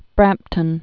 (brămptən)